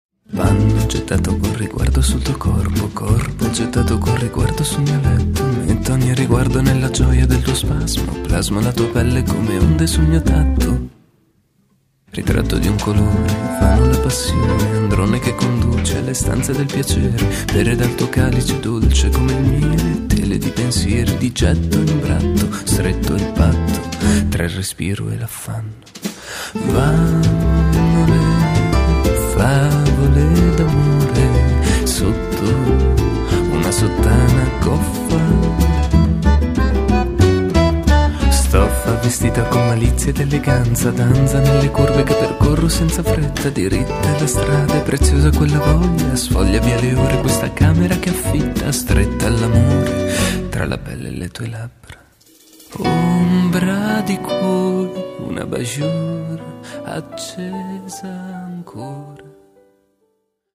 voce e chitarra classica
piano
contrabbasso
fisarmonica
chitarra acustica, classica
fiati
flicorno, tromba
violino
violoncello
Mandolino
Batteria, percussioni
lento e tutto da godere